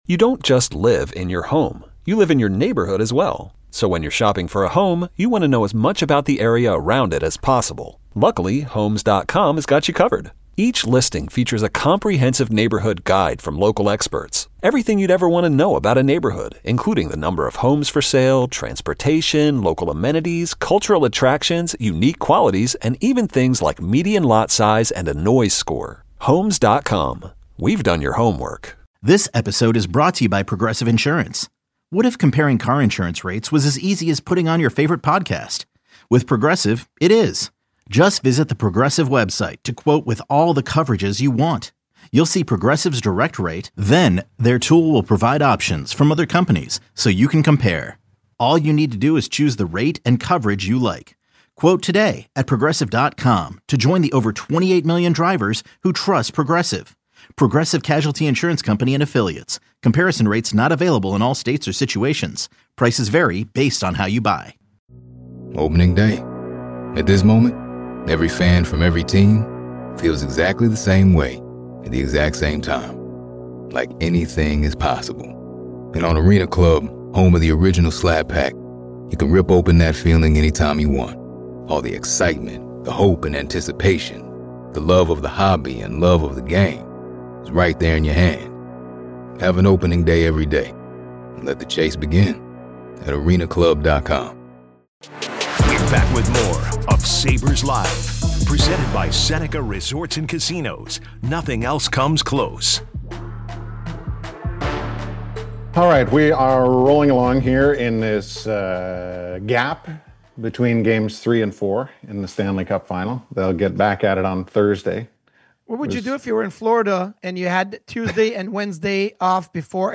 The guys talk about the new rookie of the year for the NHL.